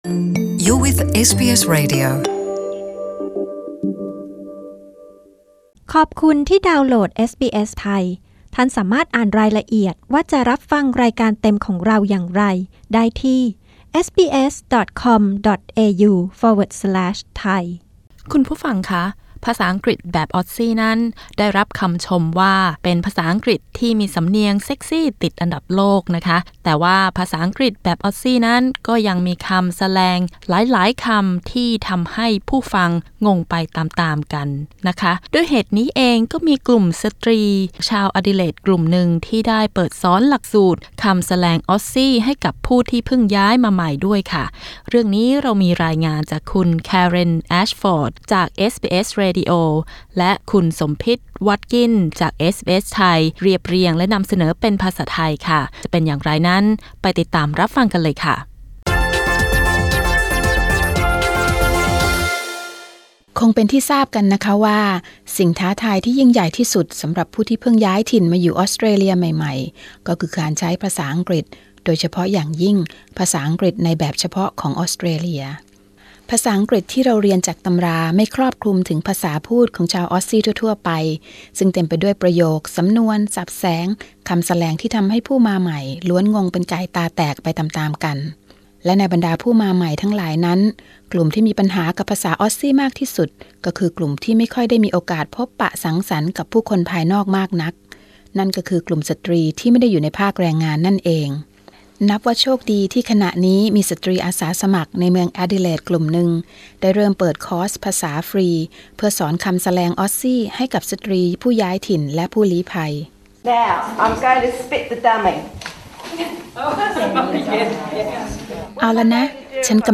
กดปุ่ม 🔊 ด้านบนเพื่อฟังสารคดีเรื่องนี้